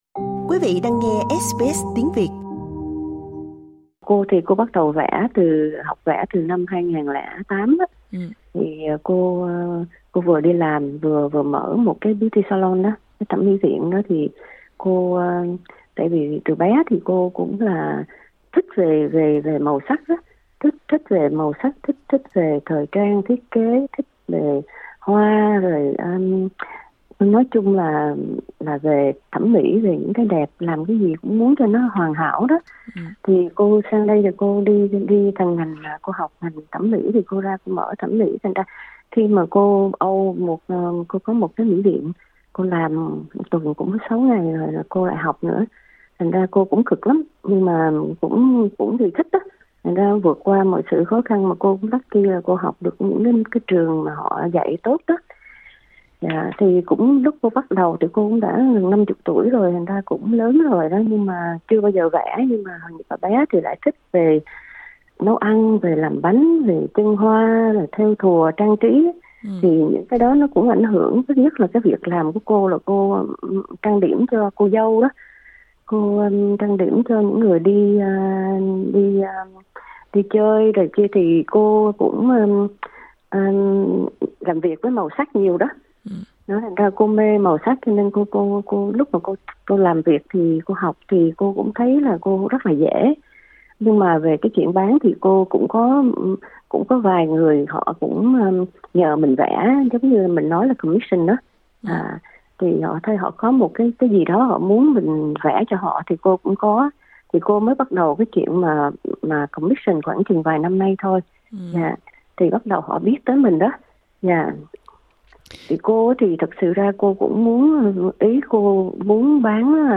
Trò chuyện với SBS Tiếng Việt